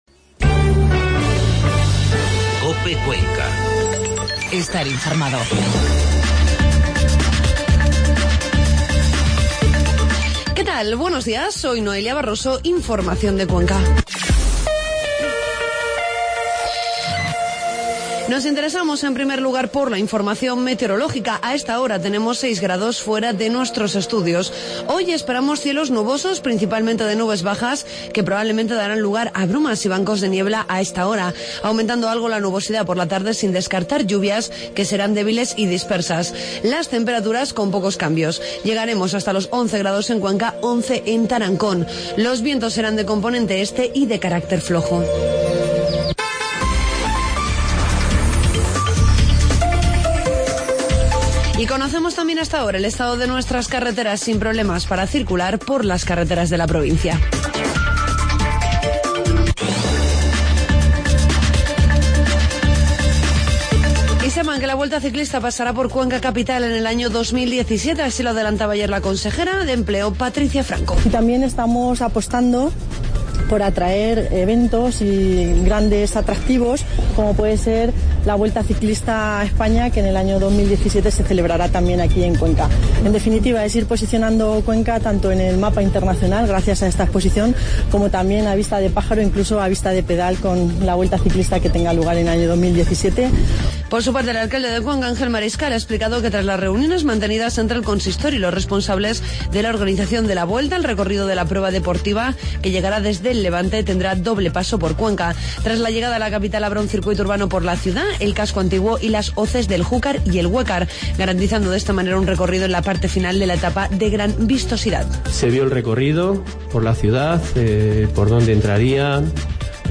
Informativo matinal COPE Cuenca.